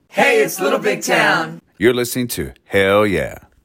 Audio / LINER Little Big Town (Hell Yeah) 4